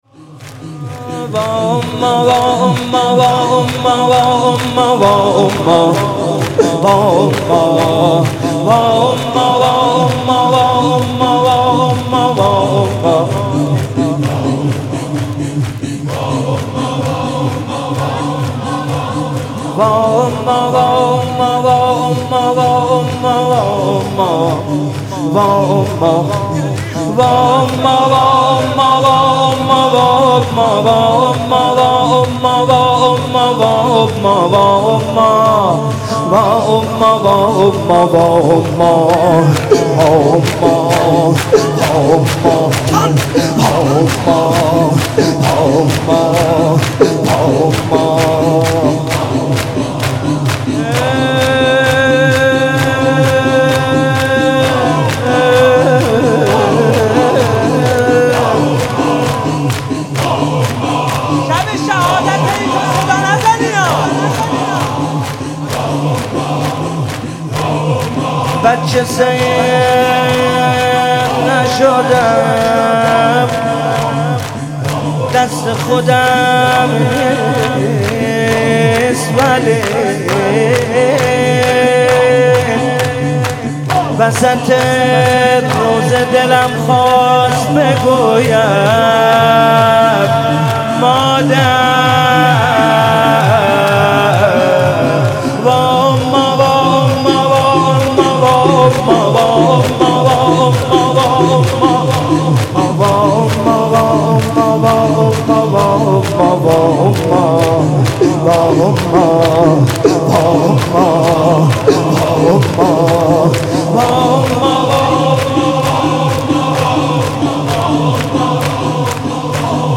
شب سوم رمضان 95، حاح محمدرضا طاهری
واحد، زمینه